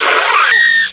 pokeemerald / sound / direct_sound_samples / cries / durant.aif
-Replaced the Gen. 1 to 3 cries with BW2 rips.